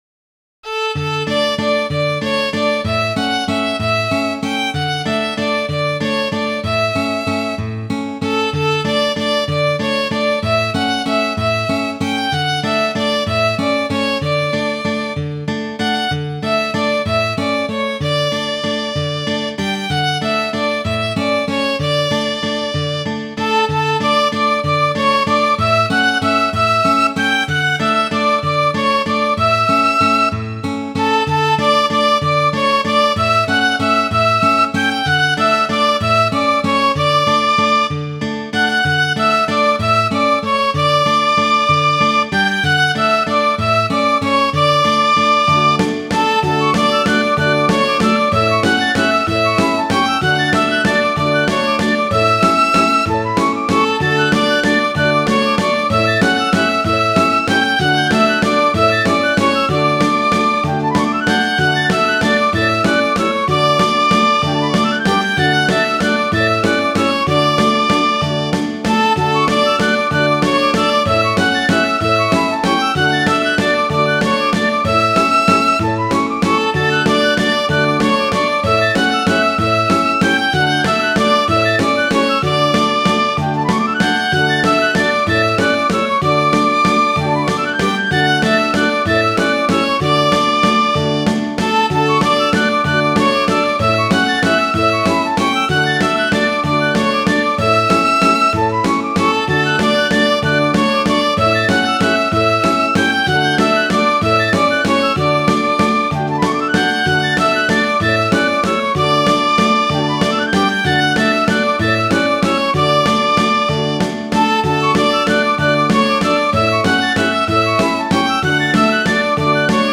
Midi File, Lyrics and Information to The Rich Lady Over the Sea